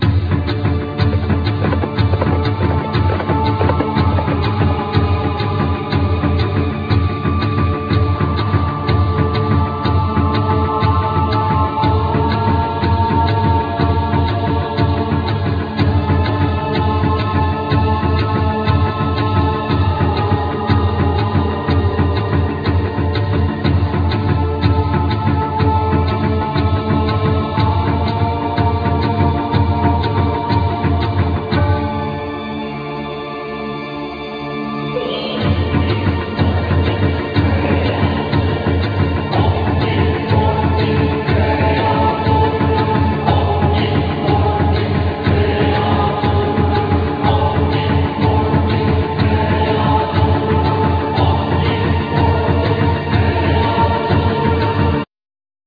Vocal, Percussions
Keyboards, Backing vocal, Drums
Violin, Backing vocal